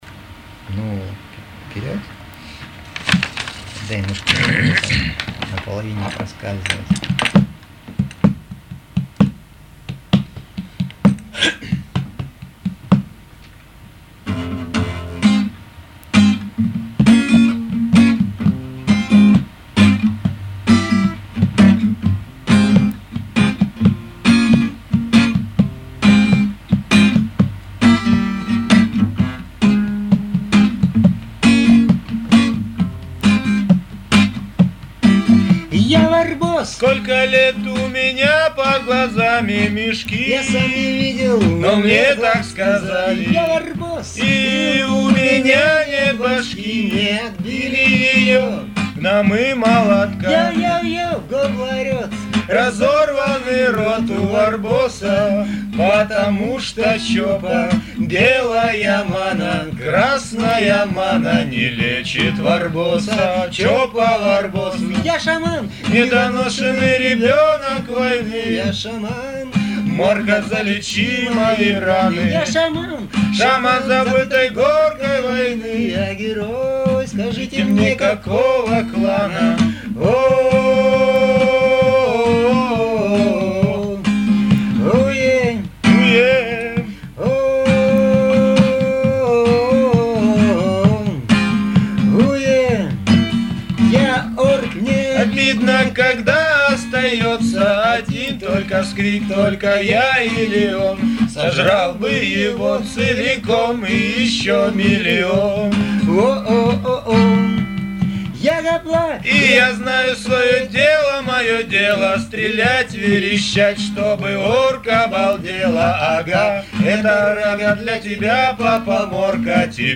• Выложено 2 варианта песни с "Принцев Пограничья": чистый вариант, более грамотный и